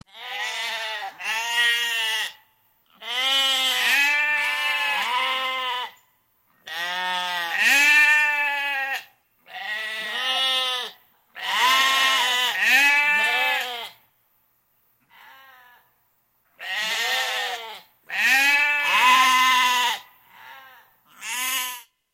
Sheep - Овцы/Бараны
Отличного качества, без посторонних шумов.
168_sheep.mp3